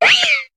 Cri de Mistigrix dans Pokémon HOME.